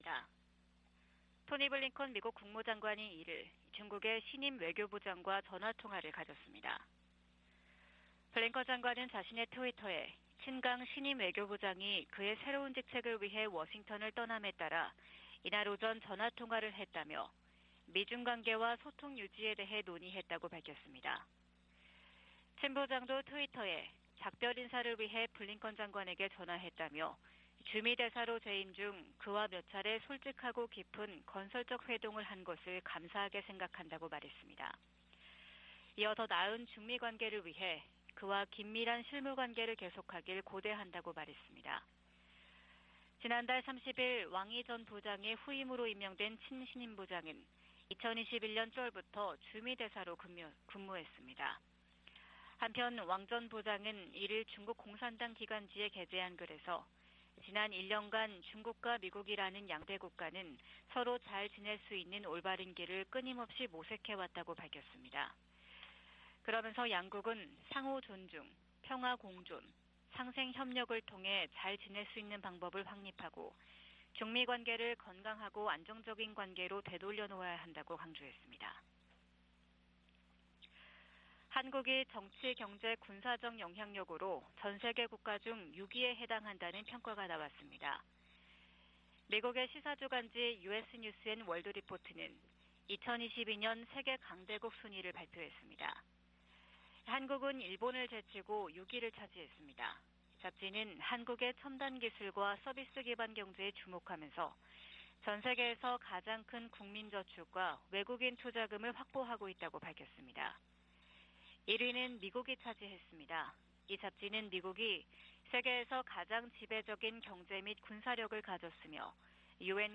VOA 한국어 '출발 뉴스 쇼', 2023년 1월 3일 방송입니다. 미국 국무부가 이틀 연속 탄도미사일을 발사한 북한을 비판했습니다.